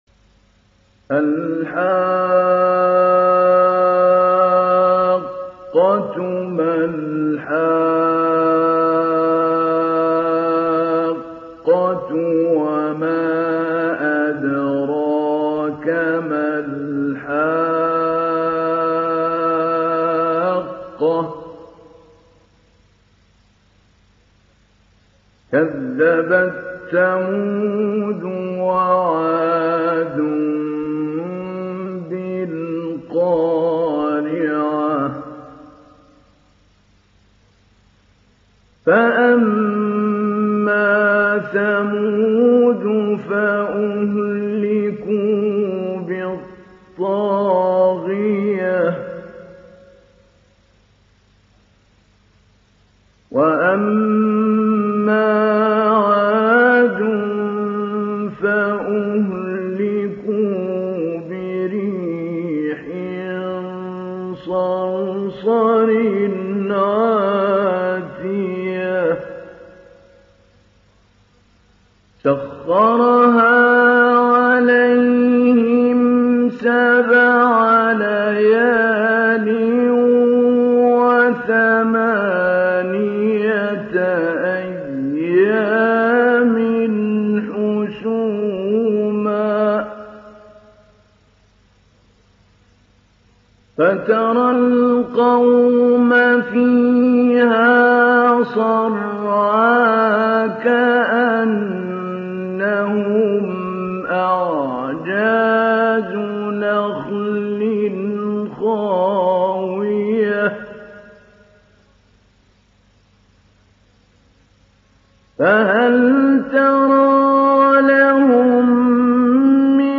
Download Surat Al Haqqah Mahmoud Ali Albanna Mujawwad